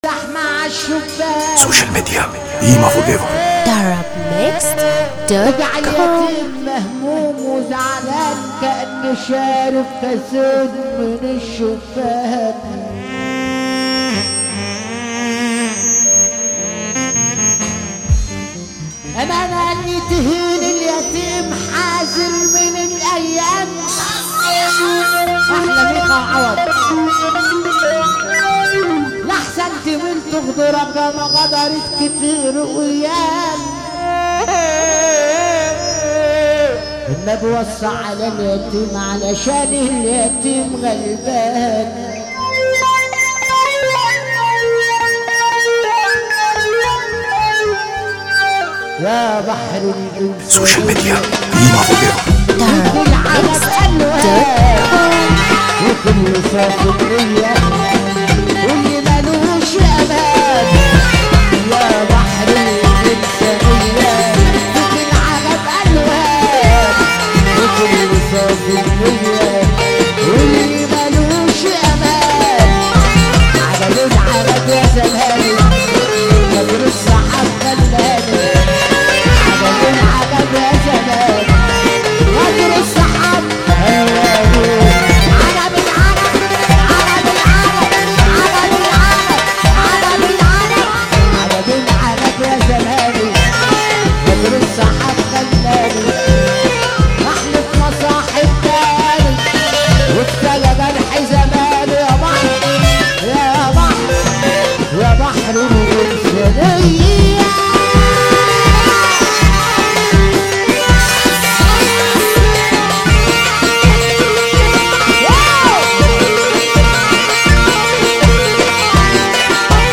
موال
حزين موت